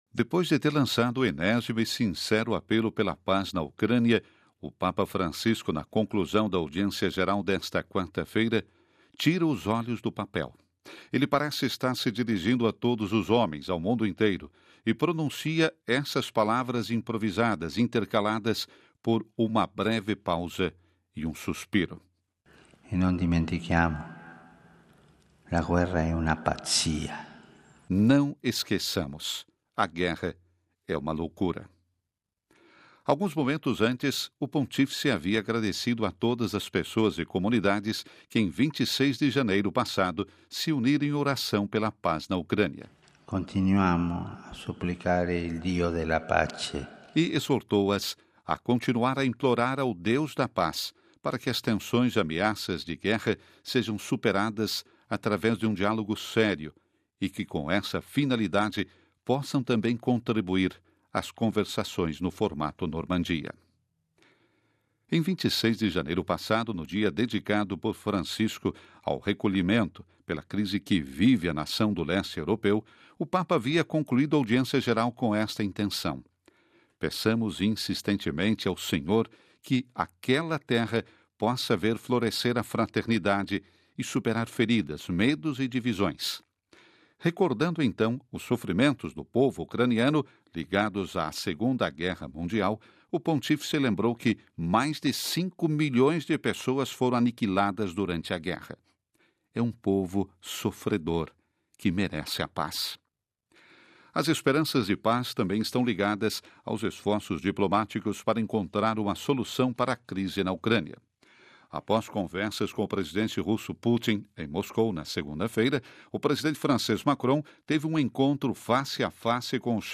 Papa Francisco durante a Audiência geral  (Vatican Media)